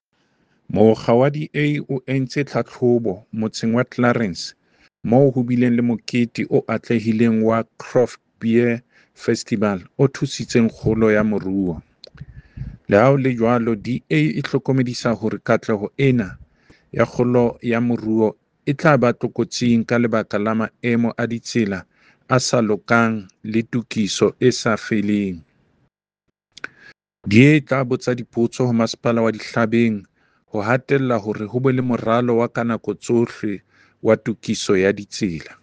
Sesotho soundbite by David Masoeu MPL with images here, here, here, and here